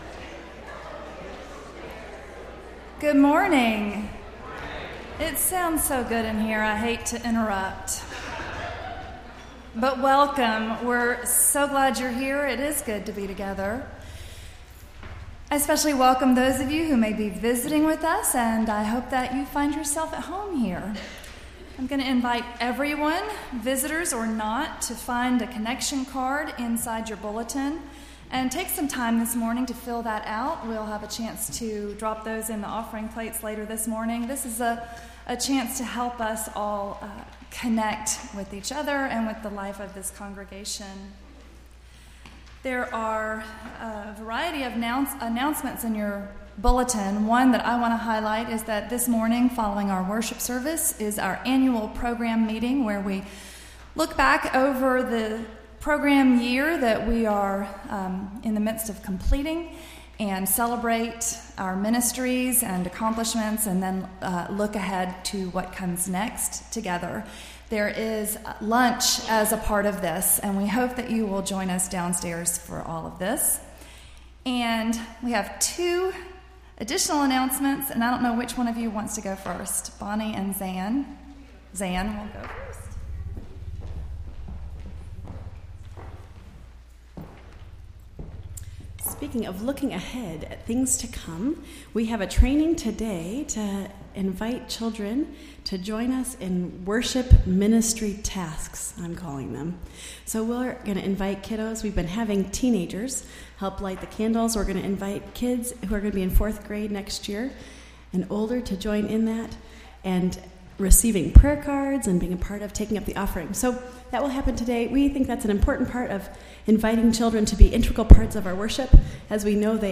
Entire April 30th Service